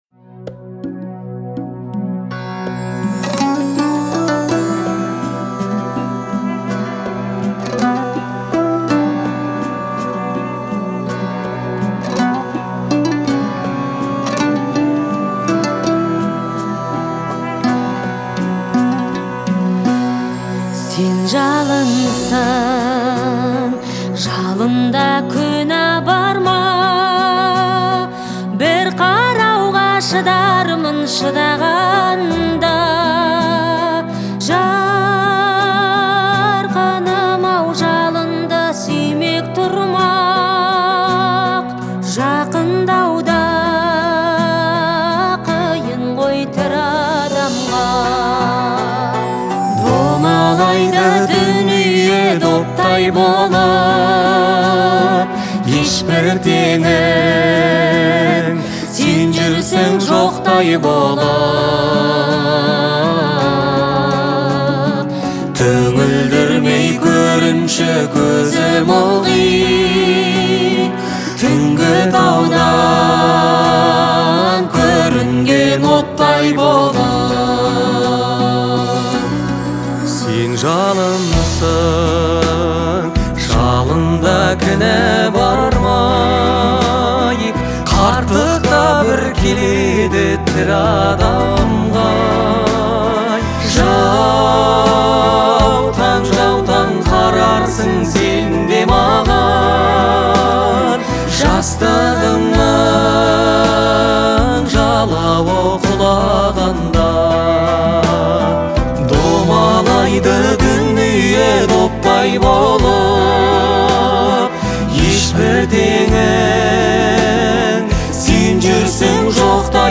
казахского поп-фолка